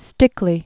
(stĭklē), Gustav 1858-1942.